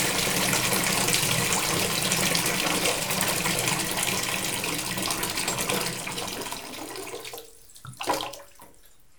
bath2.wav